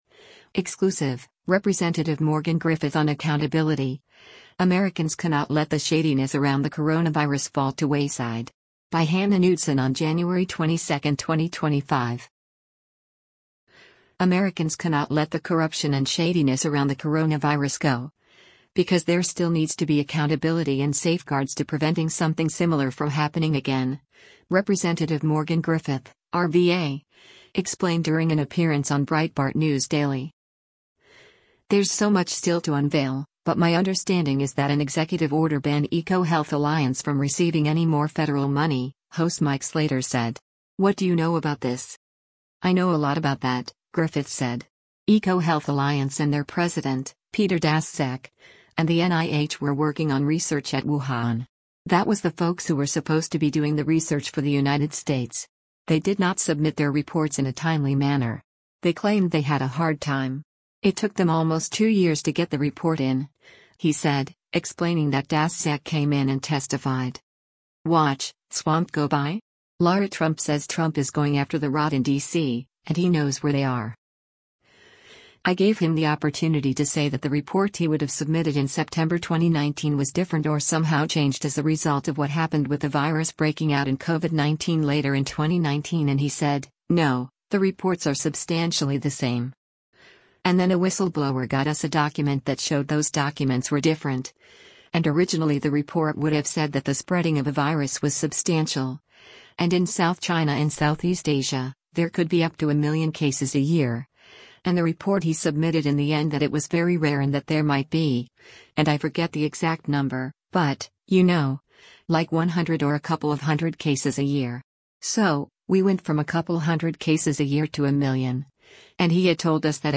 Americans cannot let the corruption and shadiness around the coronavirus go, because there still needs to be accountability and safeguards to preventing something similar from happening again, Rep. Morgan Griffith (R-VA) explained during an appearance on Breitbart News Daily.
Breitbart News Daily airs on SiriusXM Patriot 125 from 6:00 a.m. to 9:00 a.m. Eastern.